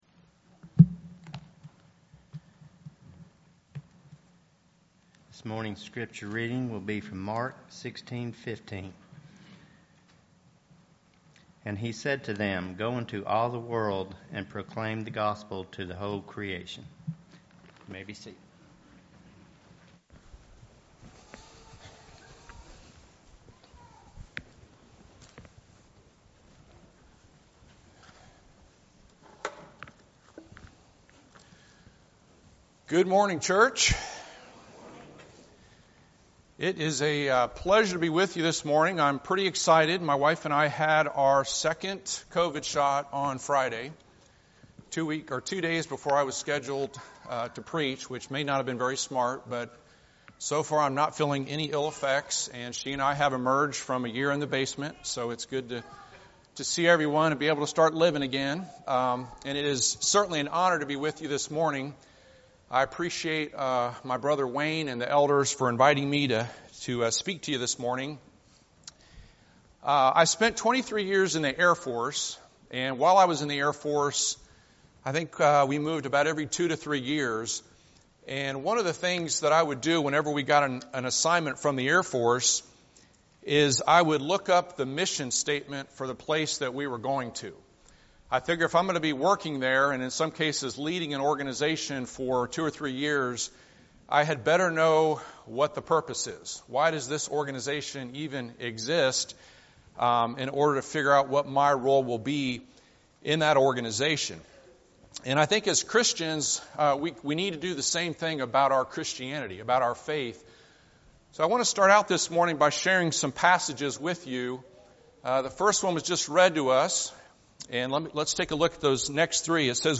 Eastside Sermons Passage: Hebrews 1:1-4 Service Type: Sunday Morning « Israel’s Historical Summary Walking Thru the Bible